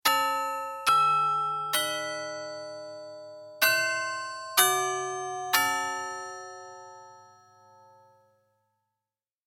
دانلود صدای ساعت 22 از ساعد نیوز با لینک مستقیم و کیفیت بالا
جلوه های صوتی